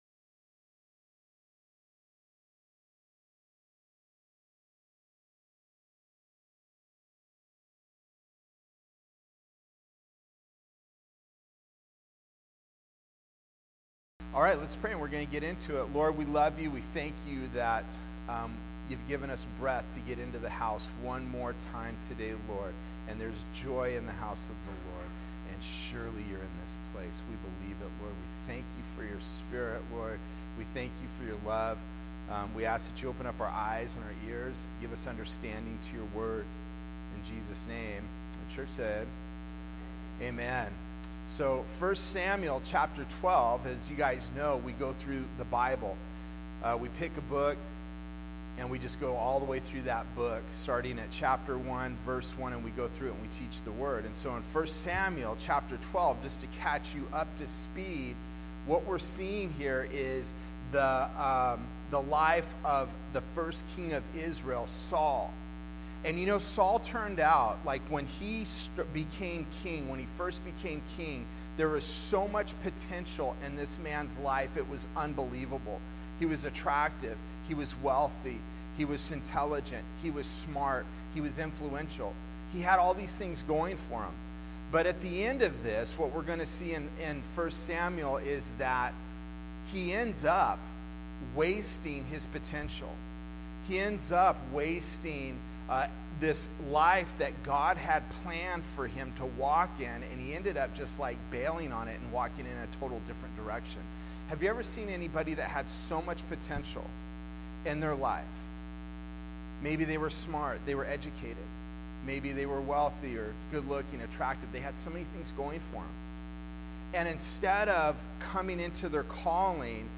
Sermons Archive - Page 17 of 47 - Ark Bible Church
A study verse by verse through 1 Samuel. Have you ever pushed for something that you were being warned against?